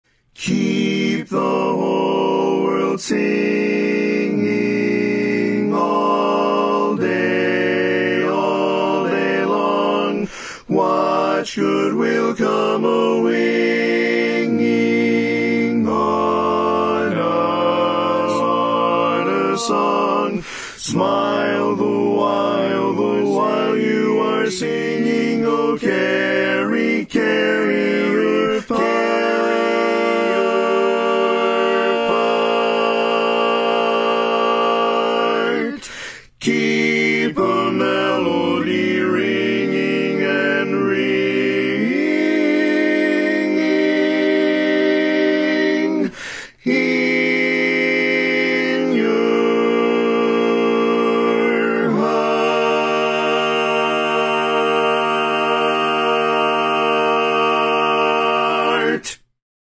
Baritone Part
Keep the Whole World Singing - Bari.mp3